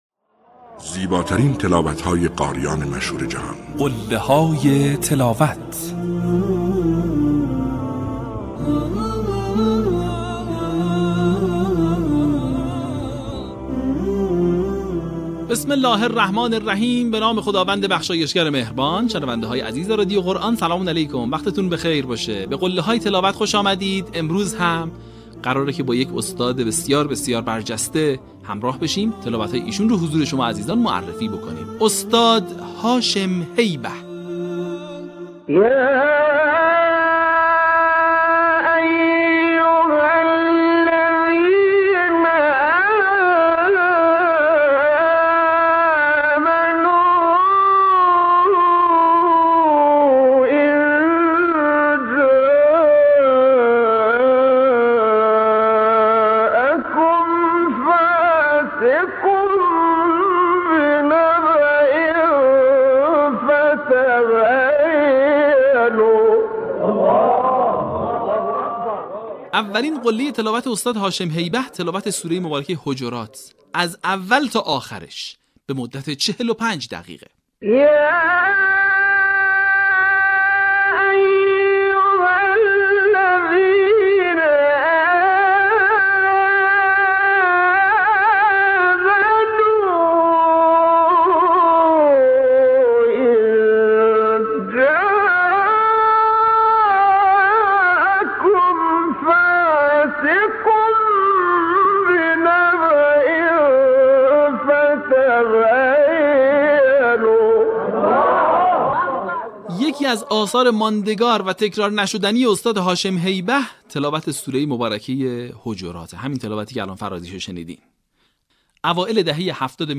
به همین منظور برترین و برجسته‌ترین مقاطع از تلاوت‌های شاهکار قاریان به نام جهان اسلام که مناسب برای تقلید قاریان است با عنوان «قله‌های تلاوت» ارائه و بازنشر می‌شود. در قسمت چهل‌ودوم فراز‌های شنیدنی از تلاوت‌های به‌یاد ماندنی استاد هاشم هیبه را می‌شنوید.